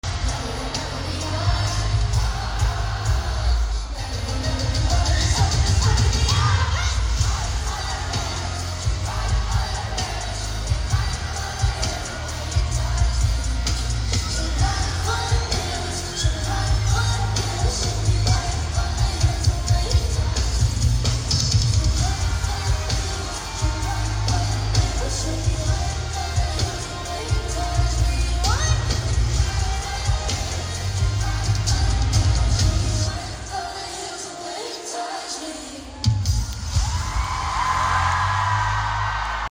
Toronto Crowd Is Feeling Lucky Sound Effects Free Download